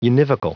Prononciation du mot univocal en anglais (fichier audio)
Prononciation du mot : univocal